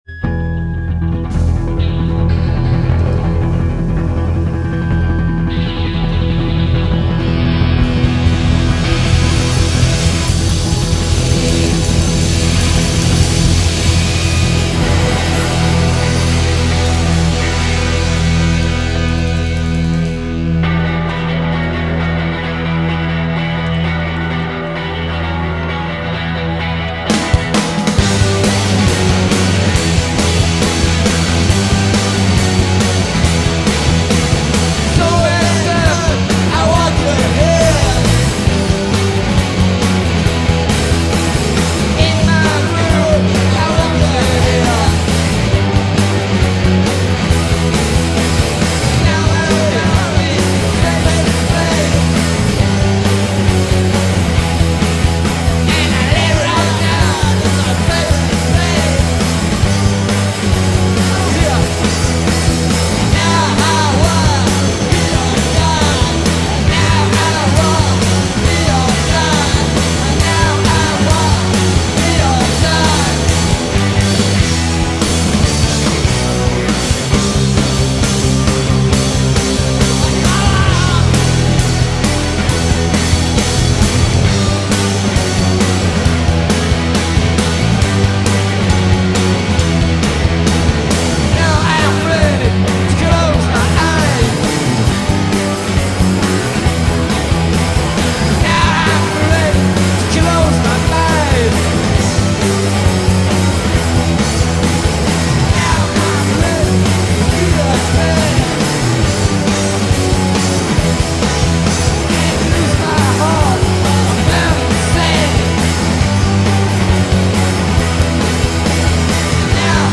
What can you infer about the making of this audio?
Tracks were recorded live